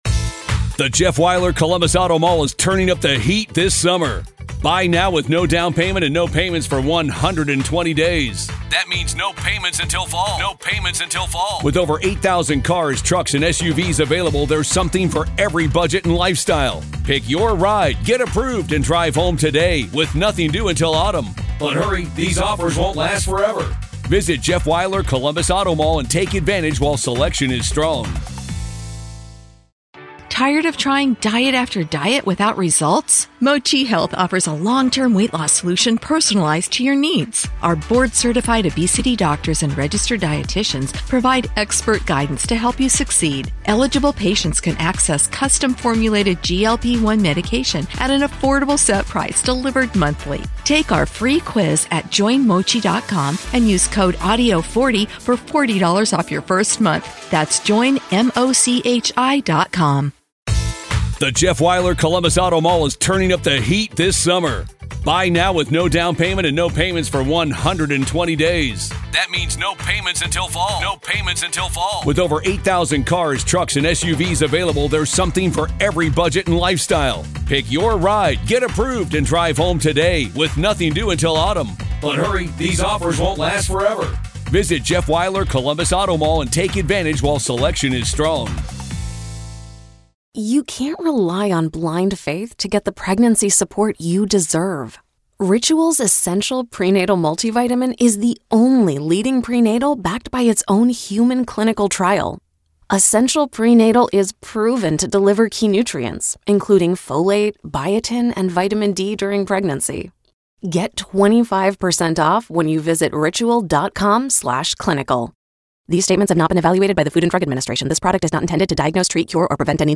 This exclusive interview reveals the prosecutorial tactics that have created an almost insurmountable credibility gap between the two sides.